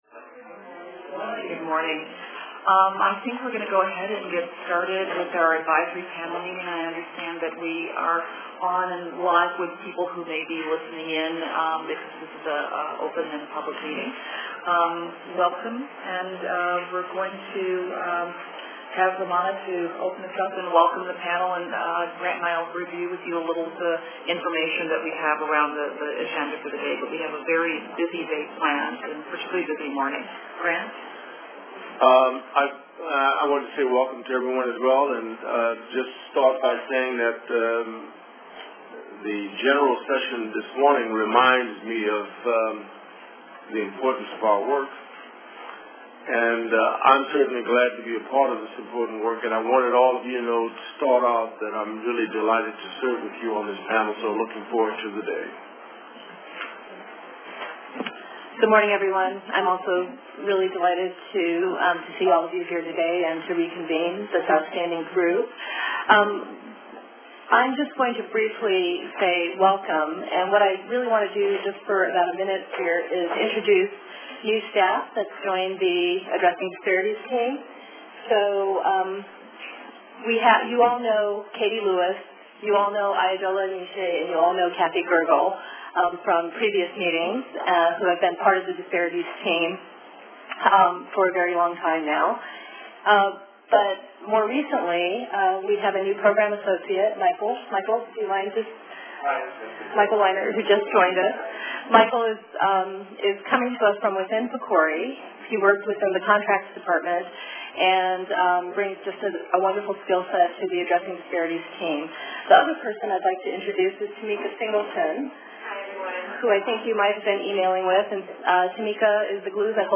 In-person attendance was restricted to panel members, but the public was welcome to listen in to the full meeting via webinar/teleconference.